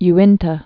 (y-ĭntə)